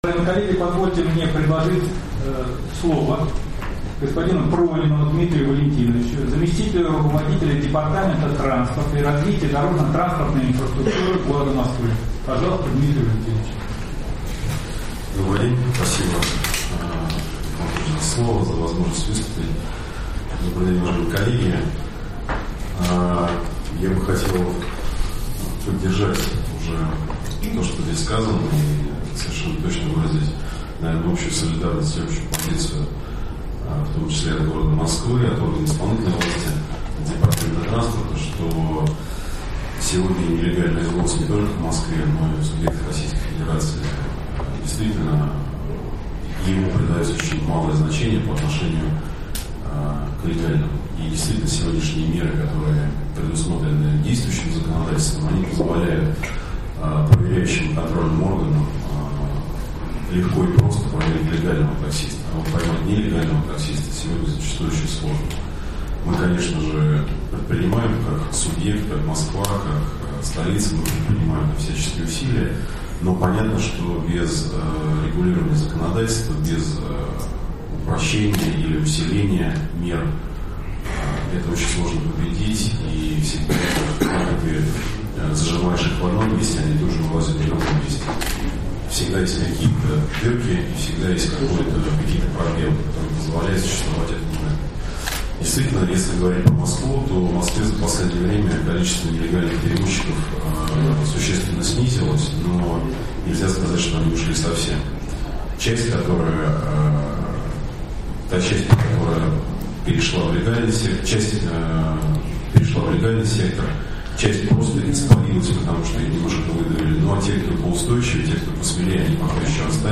Круглый стол по законодательству о такси в АЦ Правительства РФ (2)